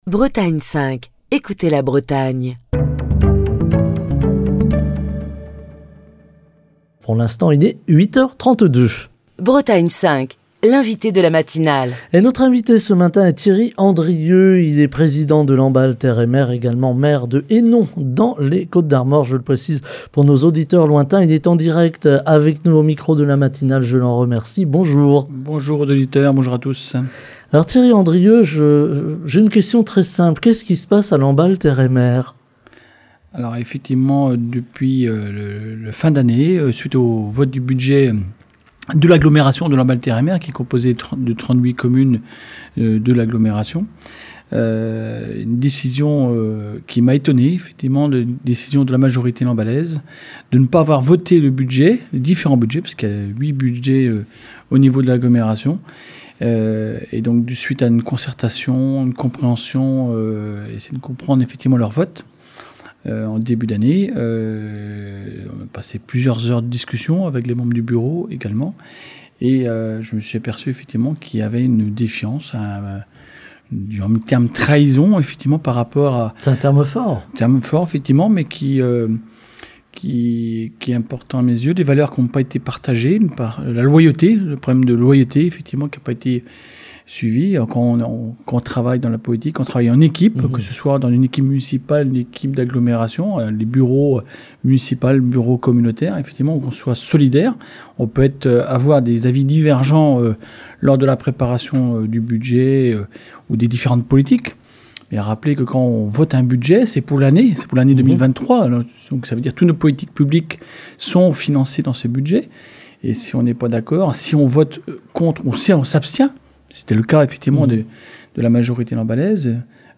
Thierry Andrieux, président de Lamballe Terre et Mer, s'en explique ce matin au micro de la matinale de Bretagne 5.